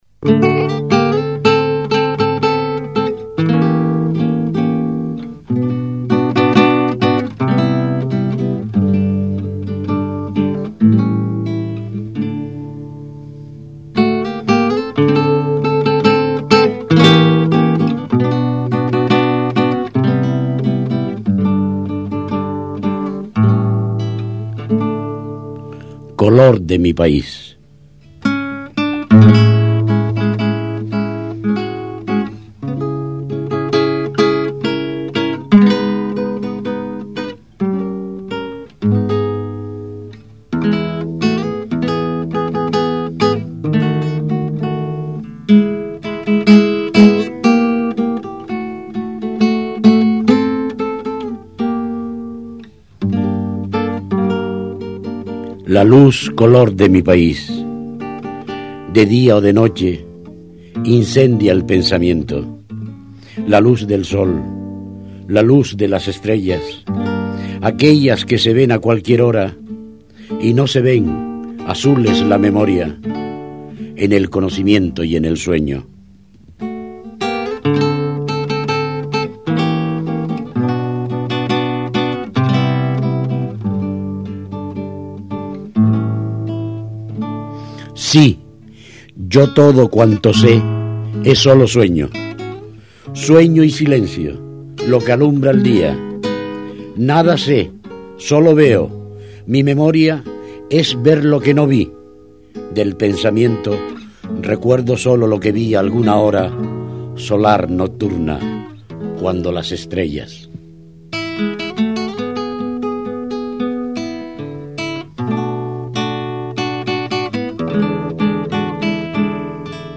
guitarra
Lectura en La Palma Está Vd. en Multimedia >> Audio FICHA TÉCNICA ARCHIVO DE SONIDO Manuel Padorno: voz